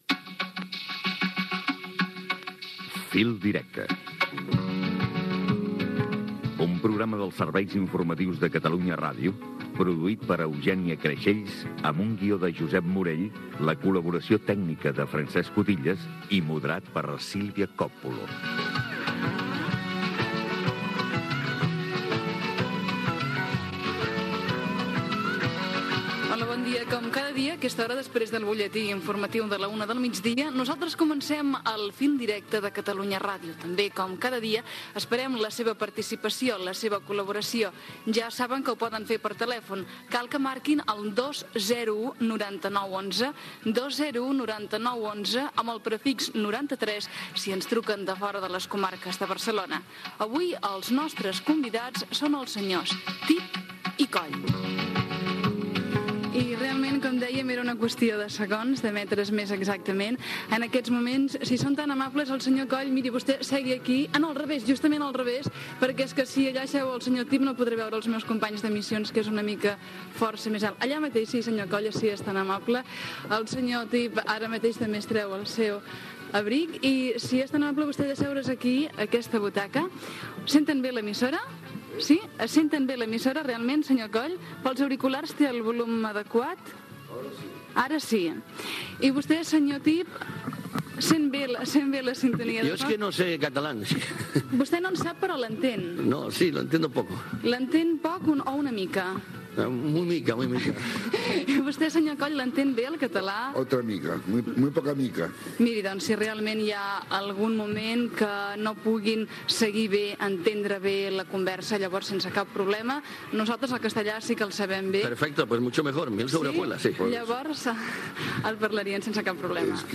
Careta del programa, presentació, telèfon de contacte, arribada dels humoristes Tip i Coll, que expressen la seva dificultat d'entendre el català, primeres preguntes dels oïdors
FM